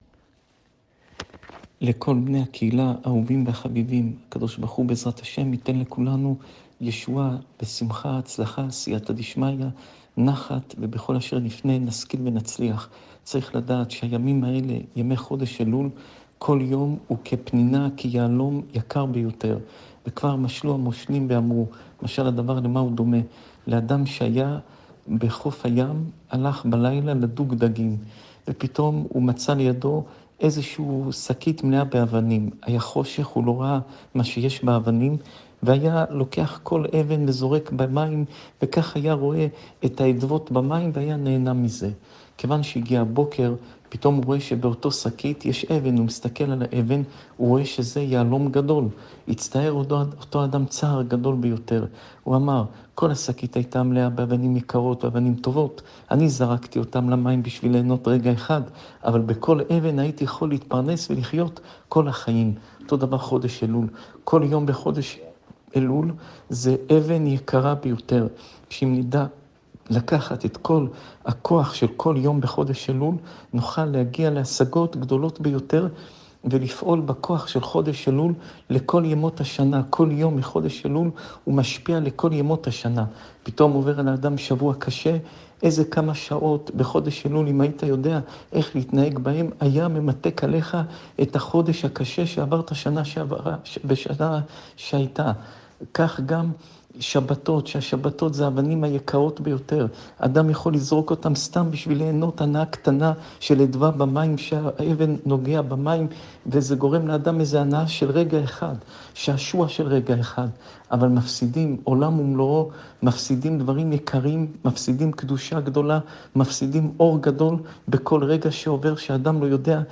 שעור תורה